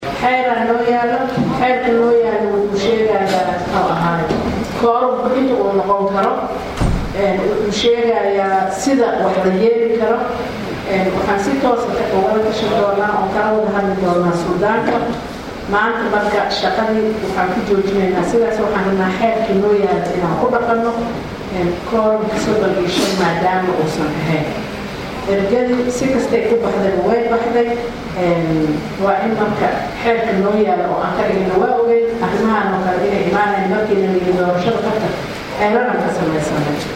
Xaliimo Ismaaciil Gudoomiyaha Gudiga doorashooyinka Madaxa banaan ayaa buuqa ka dib warbaahinta u sheegtay in dib loo dhigay doorashadda Kursigaasi.